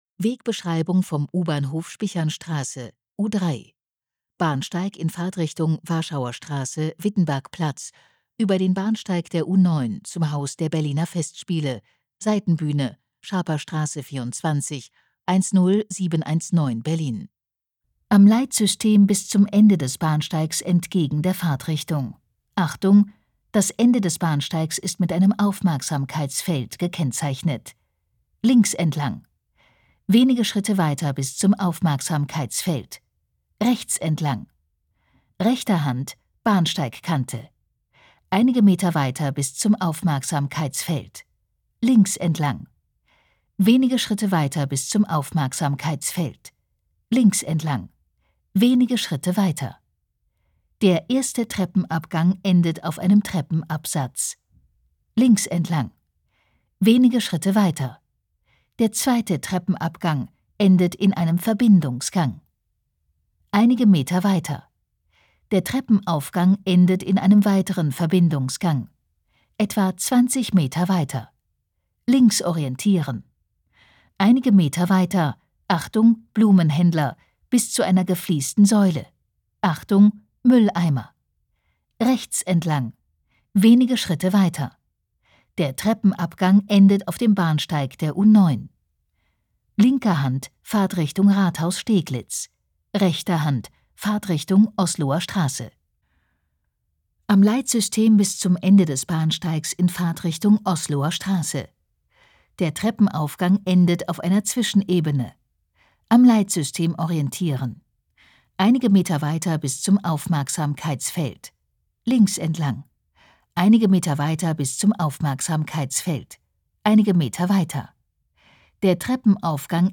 Audio-Wegbeschreibungen